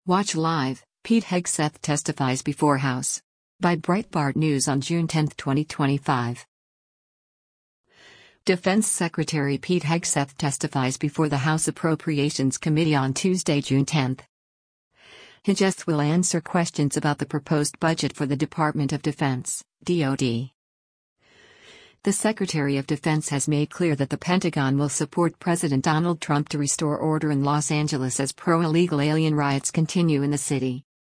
Defense Secretary Pete Hegseth testifies before the House Appropriations Committee on Tuesday, June 10.